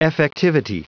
Prononciation audio / Fichier audio de EFFECTIVITY en anglais
Prononciation du mot effectivity en anglais (fichier audio)